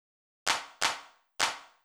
TEC Beat - Mix 15.wav